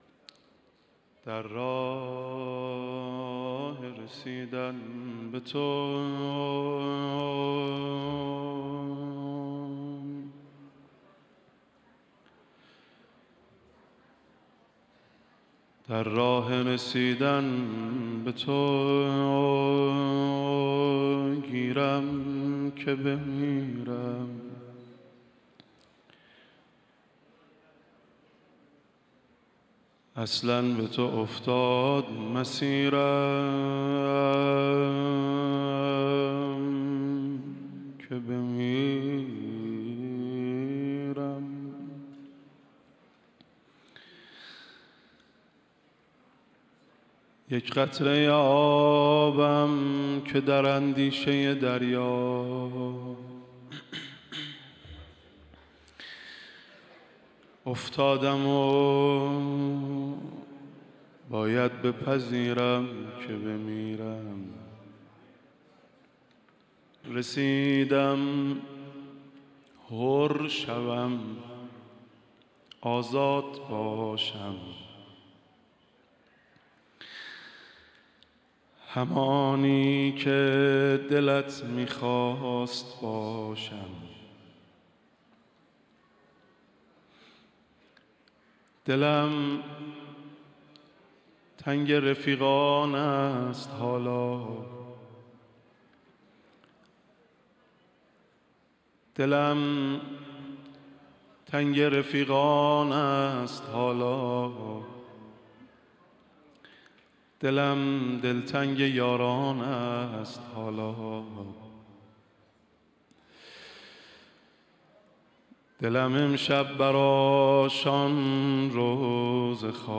مداحی آذری نوحه ترکی
در محضر رهبر معظم انقلاب